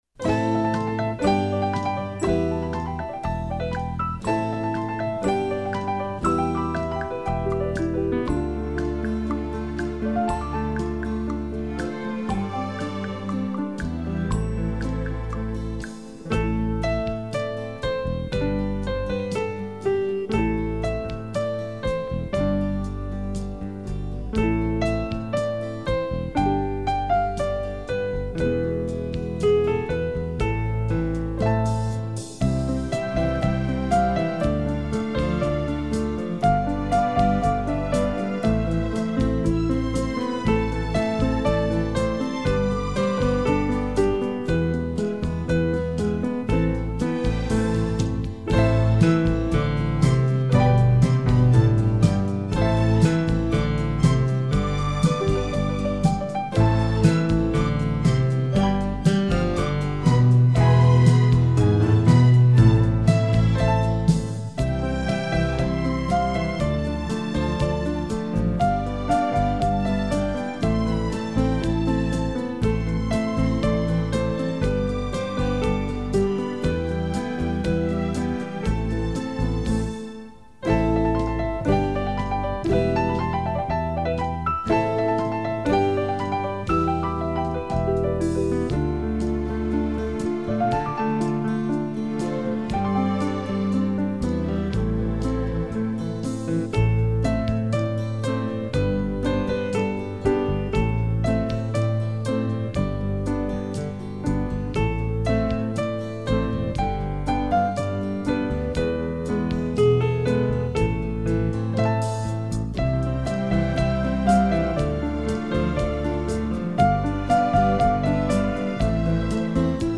[2007-4-3]【夜之夜音】 梅雪争春 - 演奏曲